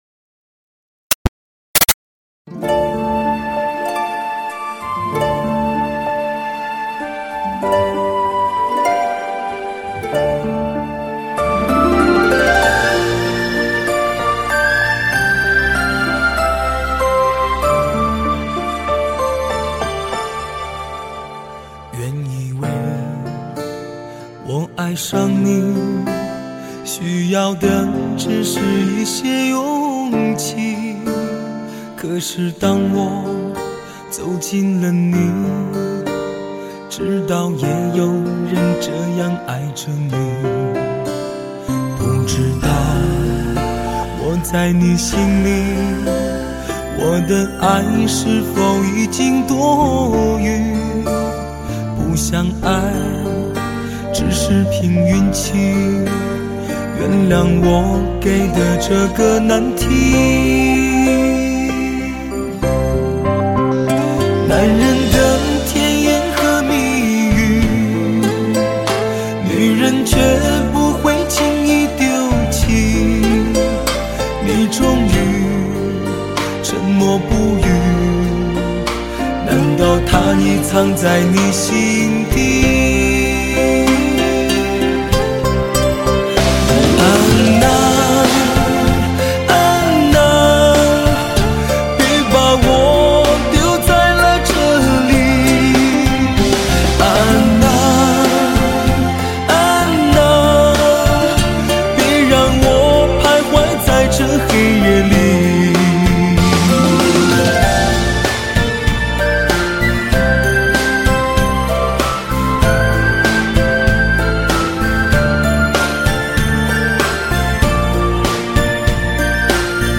高音质CD时代HI-FICD
3D音效+环绕360°